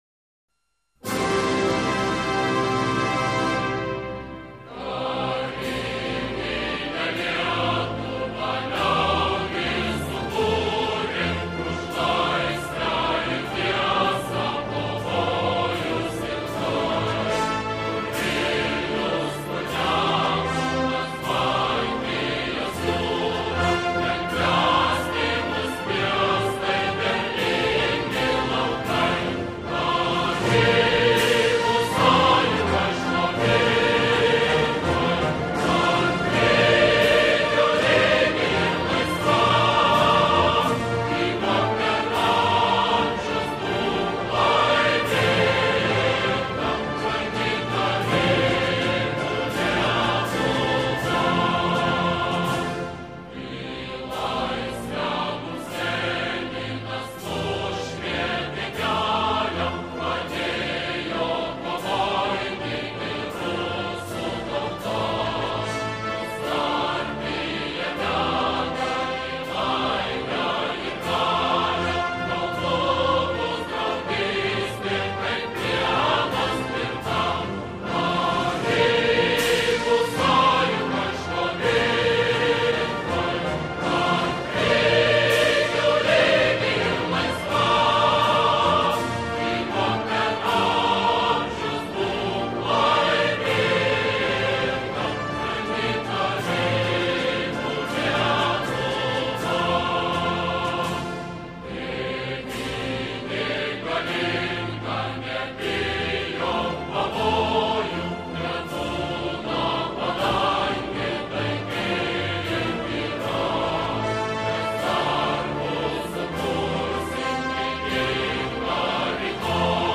結構力強く、すばらしい歌詞の国歌ですね。